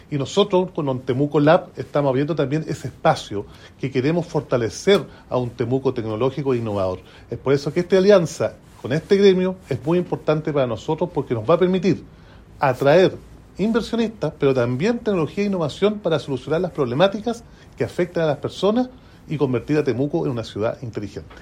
El alcalde Roberto Neira destacó la relevancia de este acuerdo.